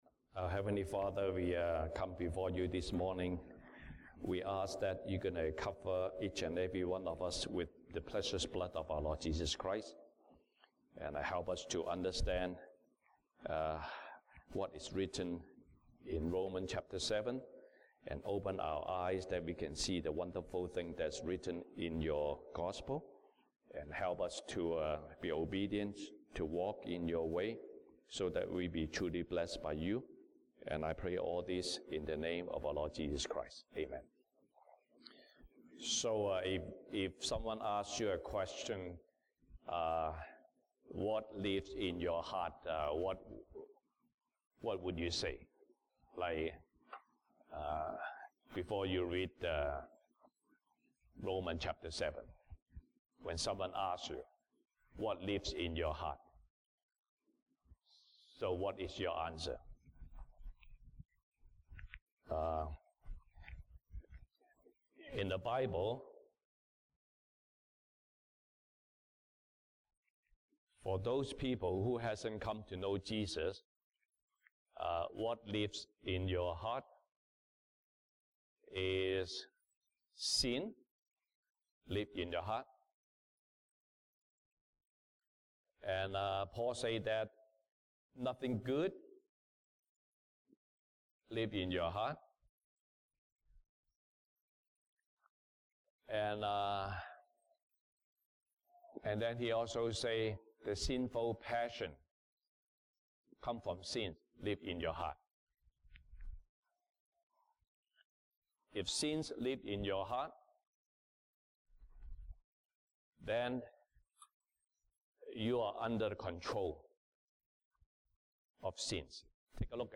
西堂證道 (英語) Sunday Service English: Who is living in me?